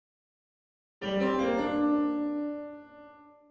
Warlike sounds and war cries are heard coming nearer.